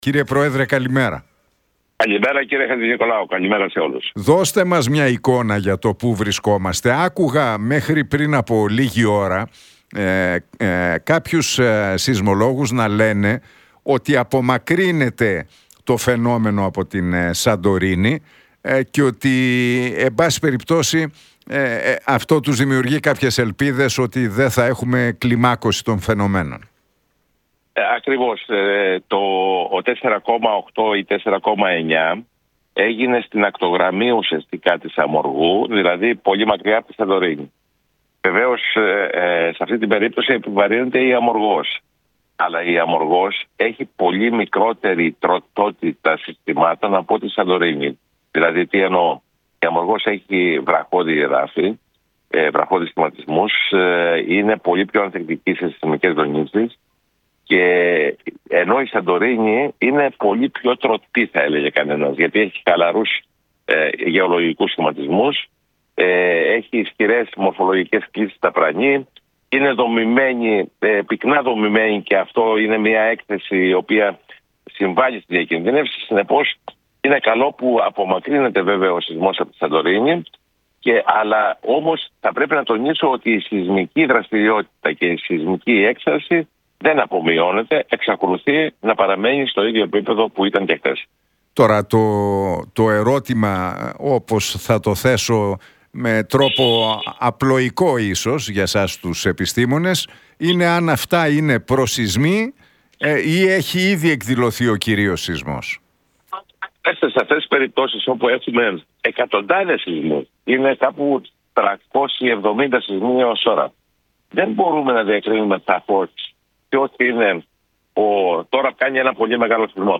Για την σεισμική δραστηριότητα στις Κυκλάδες μίλησε ο πρόεδρος του ΟΑΣΠ και καθηγητής Γεωλογίας, Ευθύμιος Λέκκας στον Νίκο Χατζηνικολάου από την συχνότητα του Realfm 97,8.